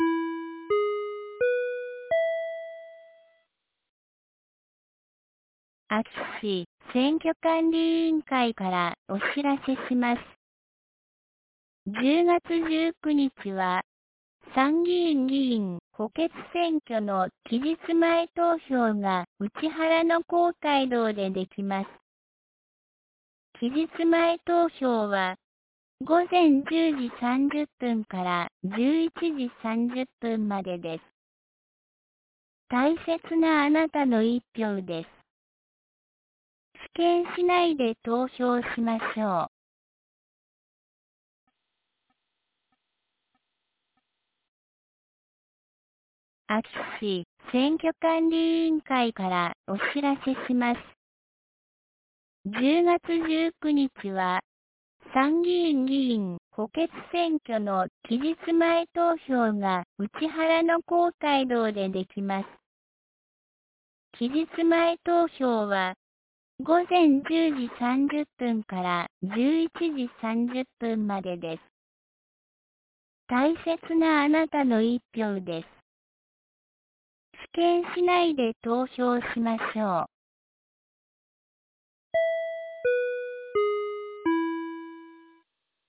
2023年10月18日 09時01分に、安芸市より井ノ口へ放送がありました。